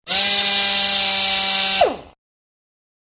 機械
電動ドライバー2（48KB）